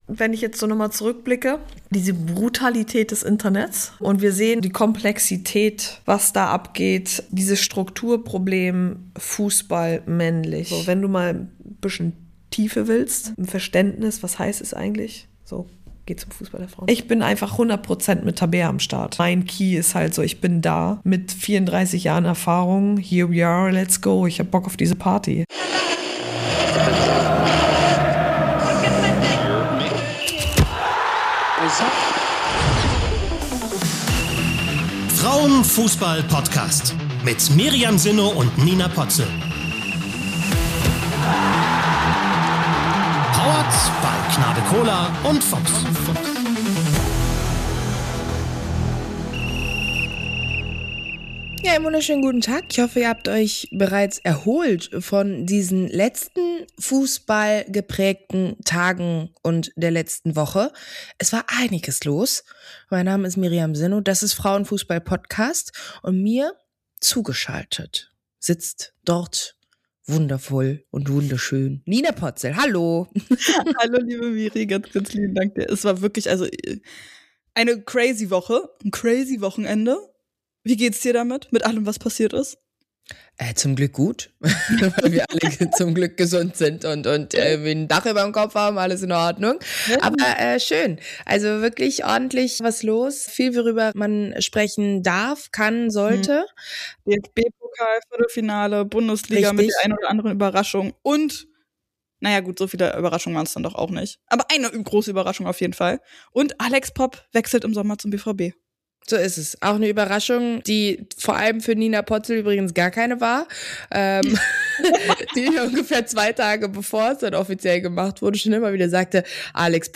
80 | "100% Tabea" | Ex-Nationalspielerin & TV-Expertin Tabea Kemme im Interview ~ Frauen. Fußball. Podcast. Podcast